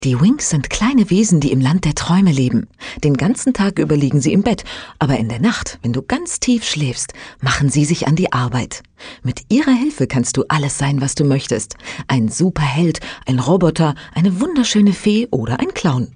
Effective Media localised Ruff & Tumble's lip-sync video sequences which almost constitute an independent film, as well as all in-game texts.
Mutter: